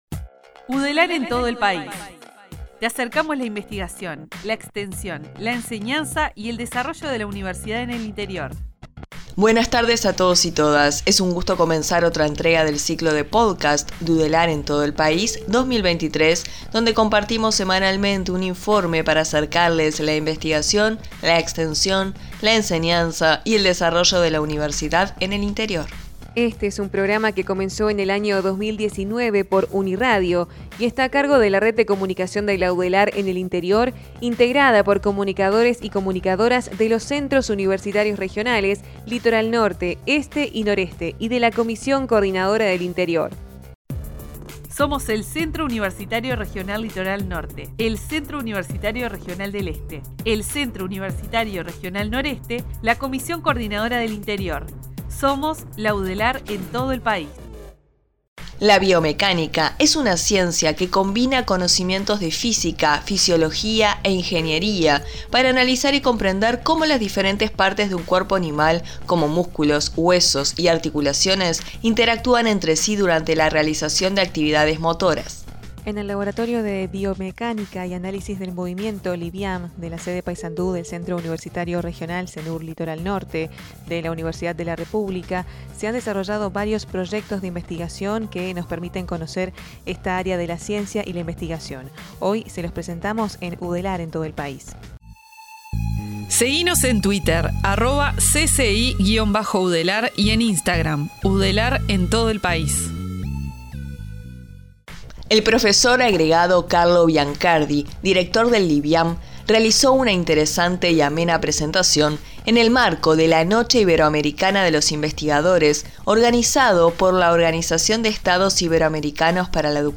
El ciclo de podcast 2023 de Udelar en todo el país te acerca la investigación, la extensión, la enseñanza y el desarrollo de la Universidad en el interior, a través de informes, con la voz de los actores universitarios, se refleja el compromiso y el trabajo de los Centros Universitarios Regionales (Cenur).